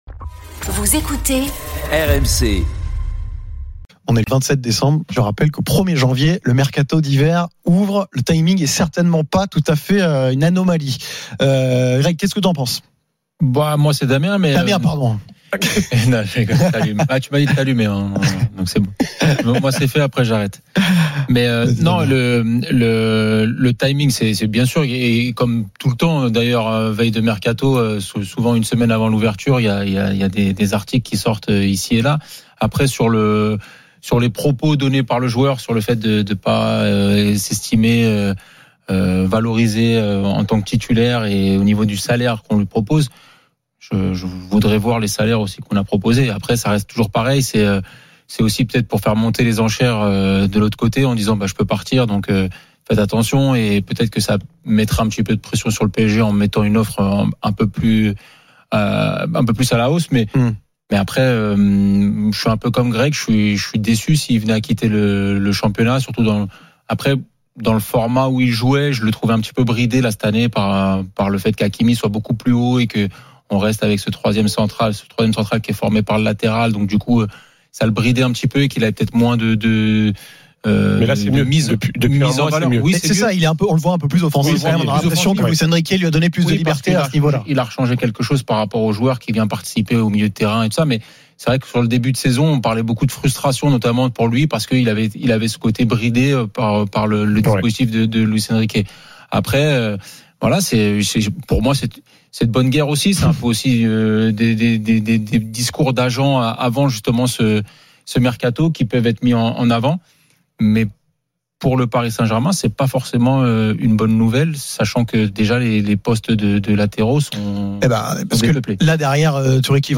Chaque jour, écoutez le Best-of de l'Afterfoot, sur RMC la radio du Sport ! L’After foot, c’est LE show d’après-match et surtout la référence des fans de football depuis 19 ans !